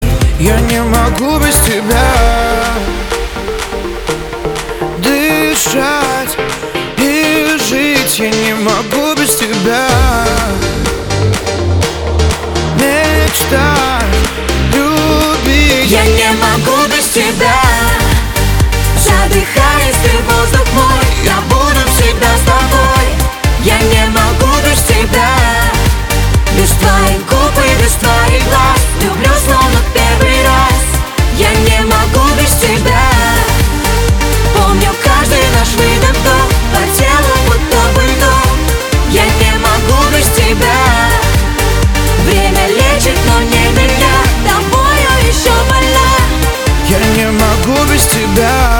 • Качество: 320, Stereo
поп
женский вокал
дуэт
энергичные